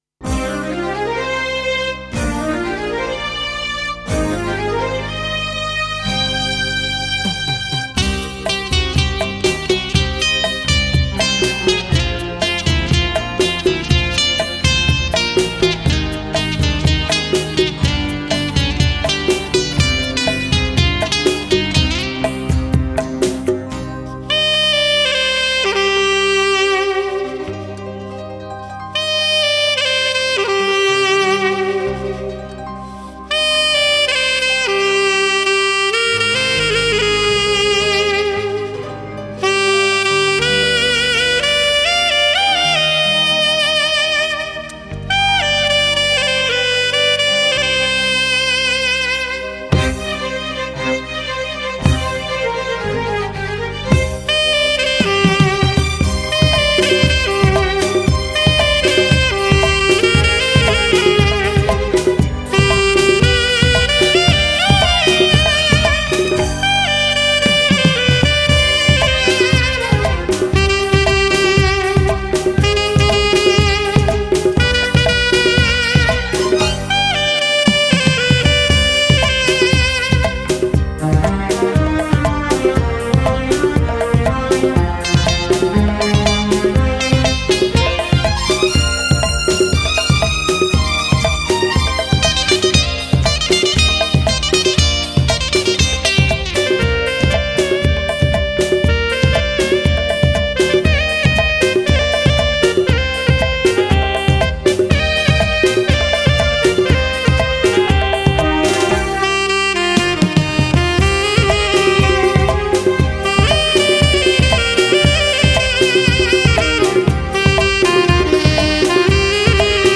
Category: Odia Karaoke instrumental Song